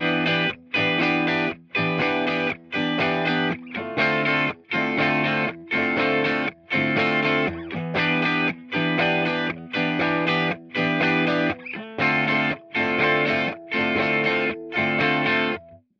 Aşağıda dublesi olmayan bir mono kayıt ile duble yapılmış stereo bir kayıdı karşılaştırabilirsiniz. Farkı daha iyi idrak edebilmek için dizüstü bilgisayar ya da mobil cihaz hoparlörlerinden değil mutlaka masaüstü hoparlörleri ya da kulaklık ile dinleyiniz.